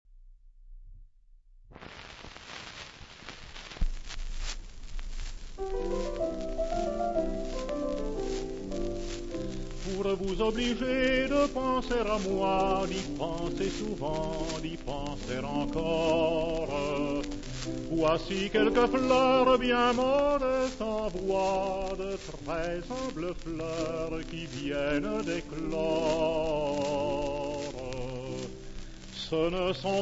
• lieder
• pianoforte
• registrazione sonora di musica